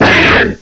cry_not_tyrunt.aif